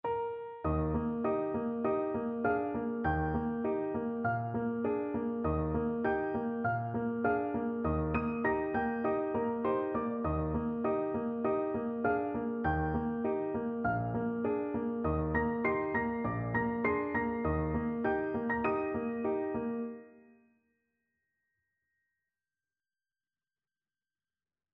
MIDILambert, Charles Lucien Sr., Au Clair de la Lune with variations, Op. 30, mm.1-8